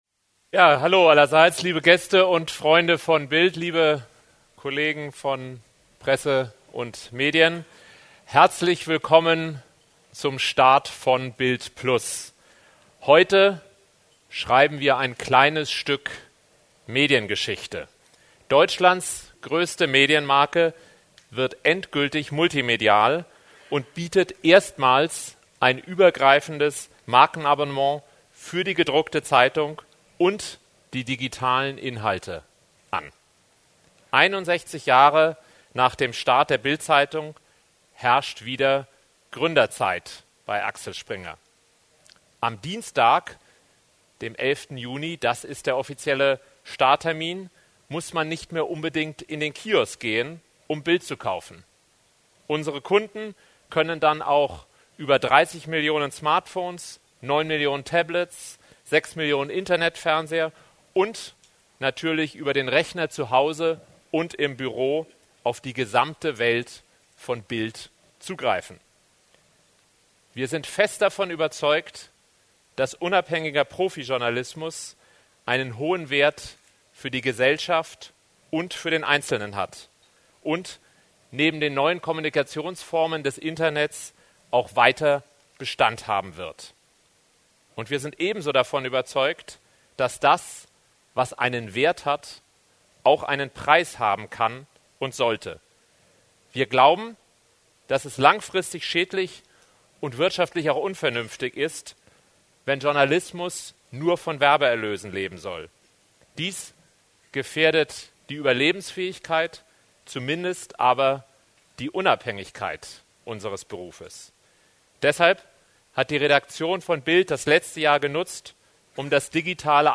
Was: Präsentation Bild+, Einführung
Wo: Berlin, Axel-Springer-Hochhaus, 19. Etage